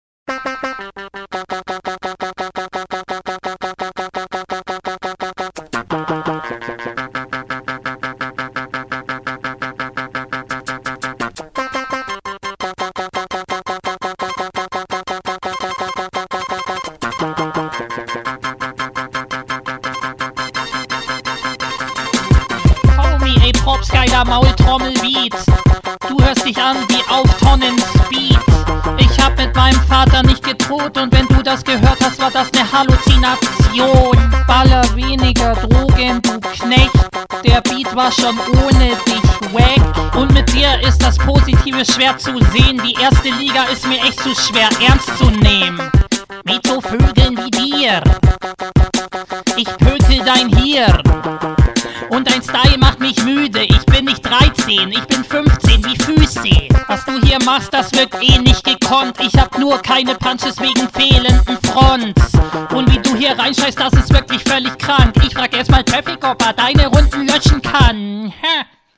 Fand den Flow deutlich schwächer aber war irgendwo im Takt und wahrscheinlich mit Absicht.